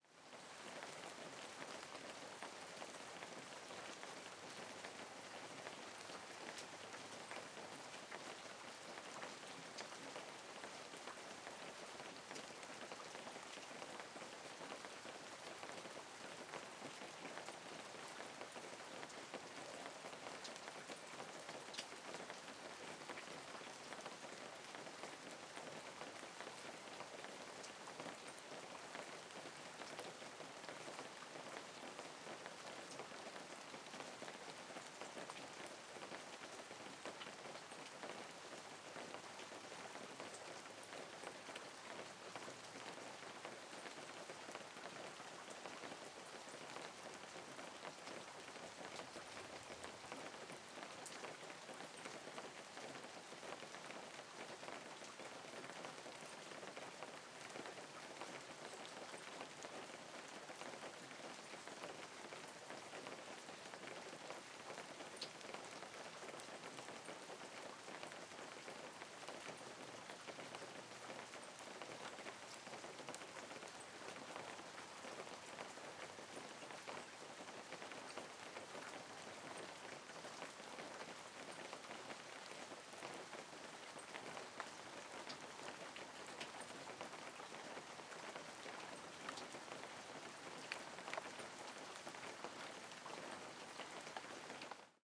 Rain light 2 (rural)
atmosphere background BG cinematic drops field-recording FX light sound effect free sound royalty free Movies & TV